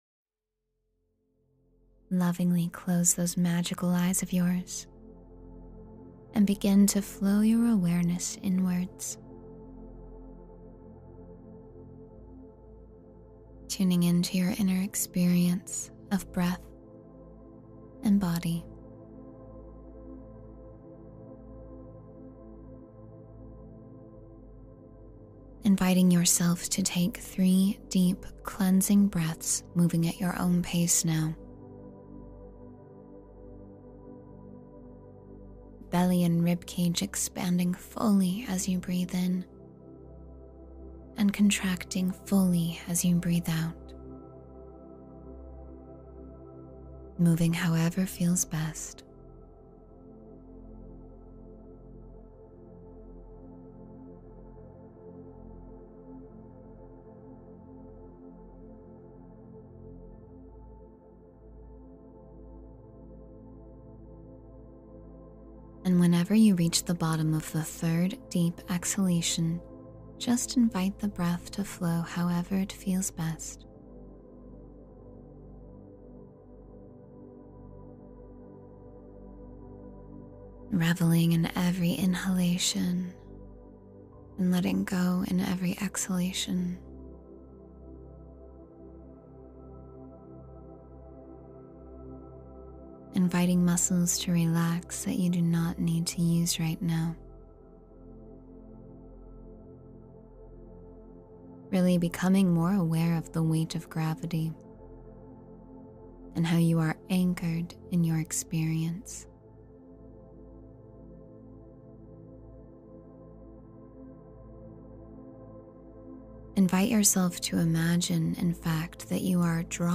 Ground and Protect Your Energy with Peace — Meditation for Energy Protection